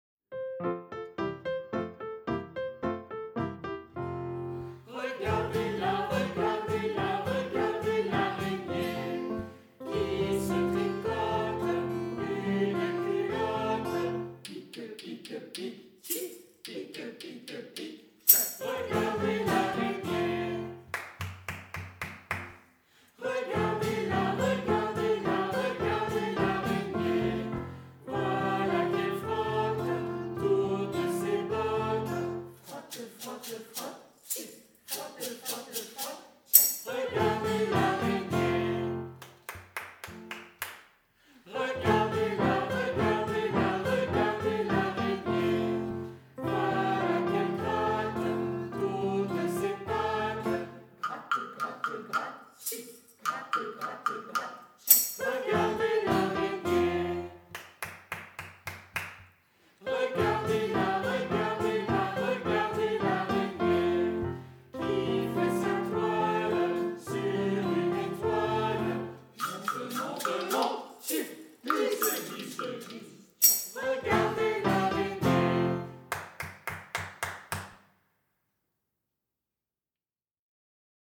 Chanson
où se marient onomatopées, percussions et chants.